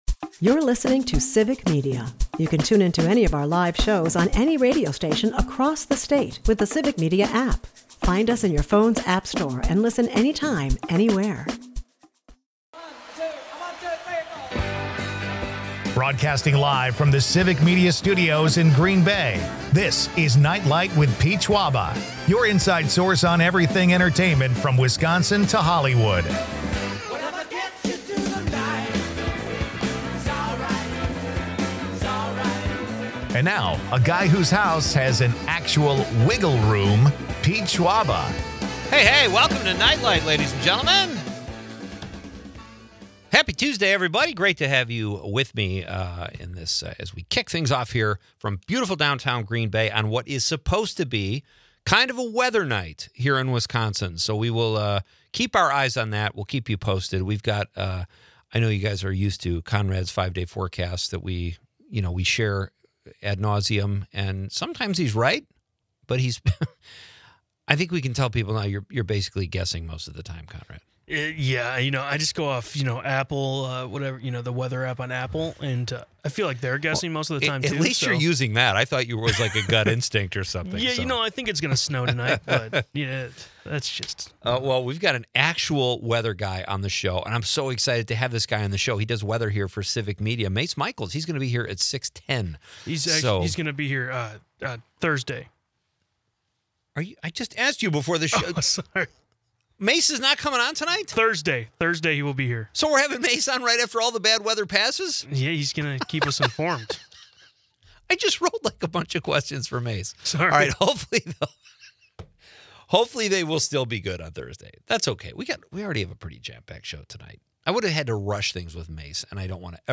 Listeners chime in with their favorite Jack Nicholson movies, celebrating the actor's 88th birthday.